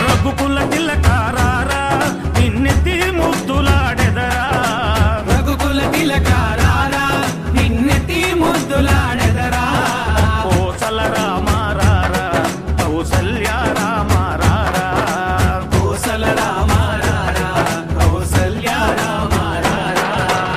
Ringtone File
Devotional Songs